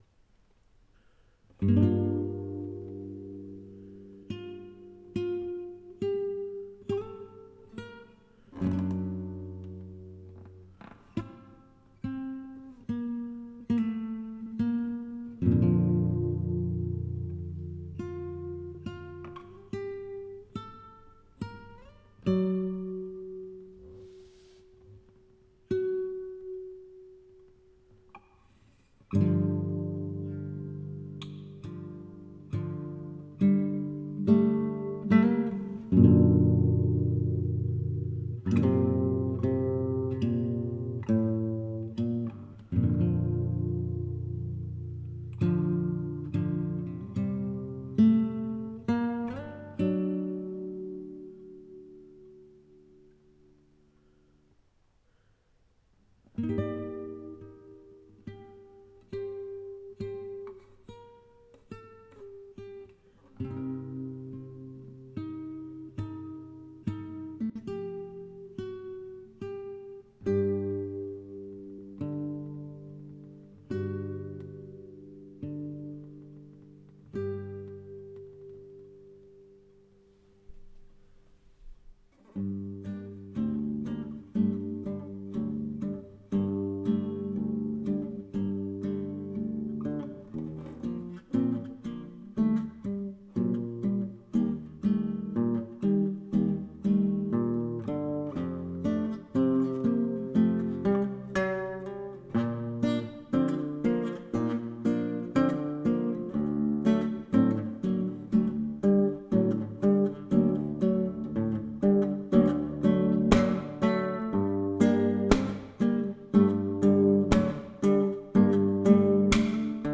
Solo guitar stuff - Portfolio sound design